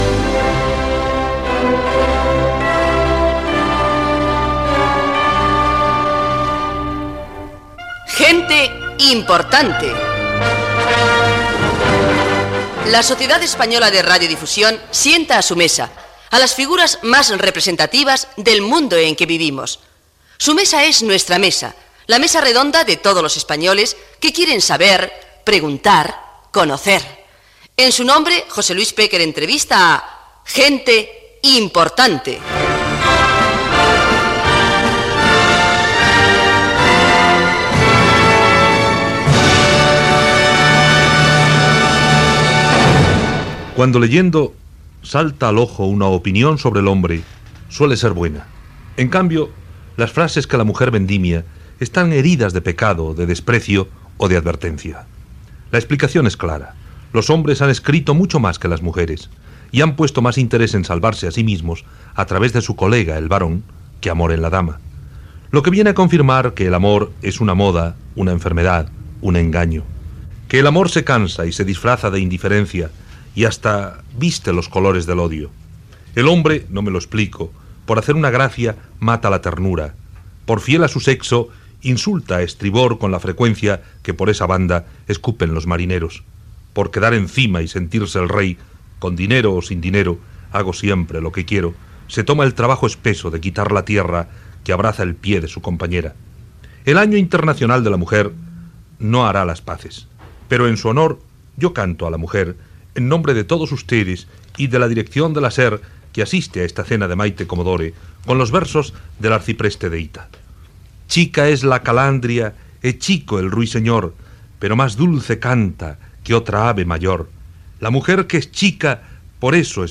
b4e67f190492fde78029cfde4877a762236e2350.mp3 Títol Cadena SER Emissora Ràdio Barcelona Cadena SER Titularitat Privada estatal Nom programa Gente importante Descripció Careta del programa, el biax dels escrits sobre les dones, menú del sopar i impressió de Mayte Commodore sobre la invitada, entrevista a la jurista Mercedes Formica.
Gènere radiofònic Entreteniment